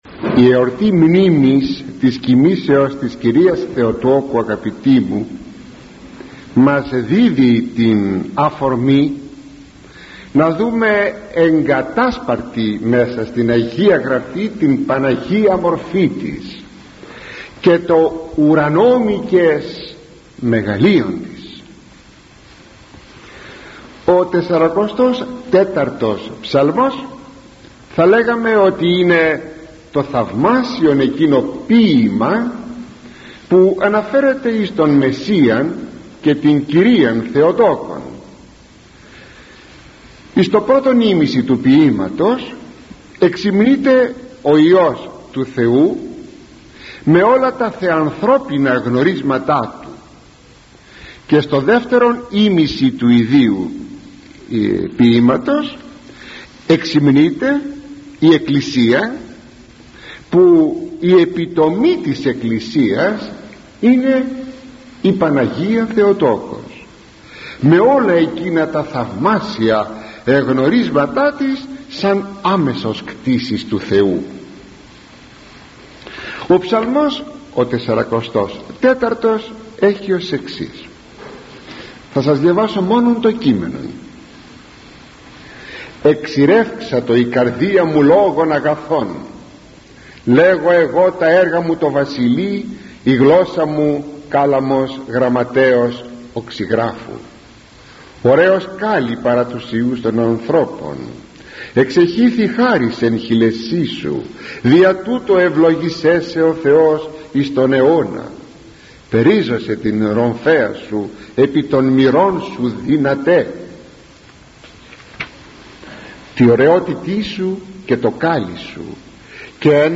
Απομαγνητοφώνηση ομιλίας